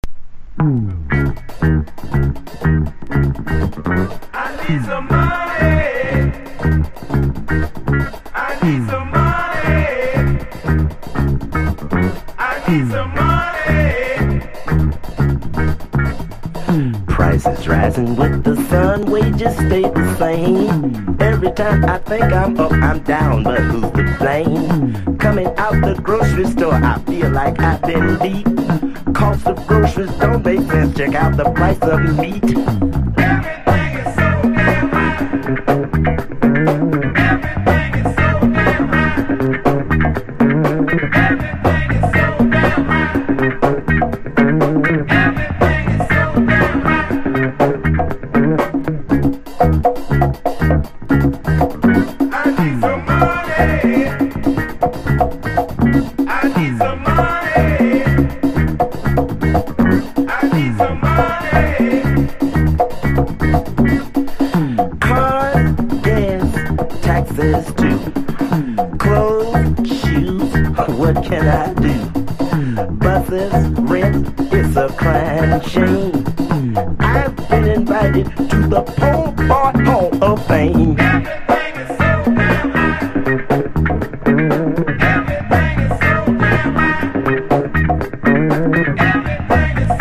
リズムマシーンを多様するなど、新しい手法に取り組むも、基盤は“ファンク”。